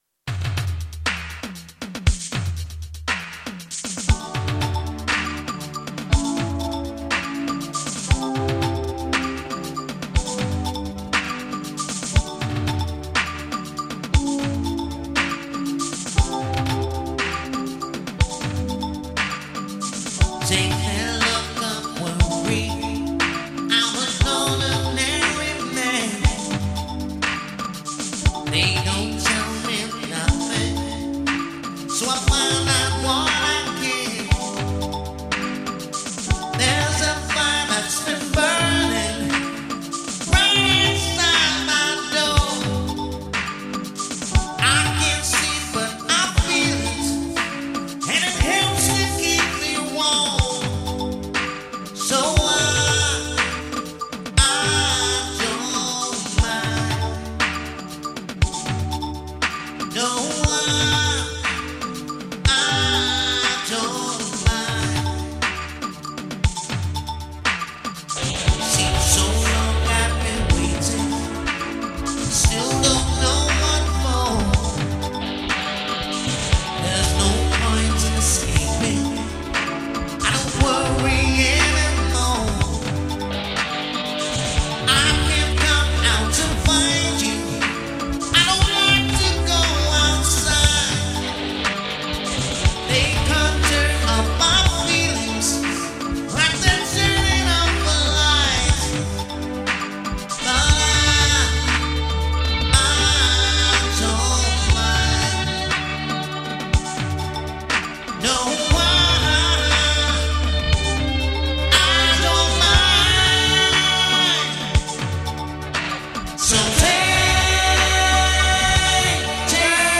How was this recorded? • Full production show with Live Band Available